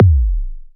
sub_swoop.wav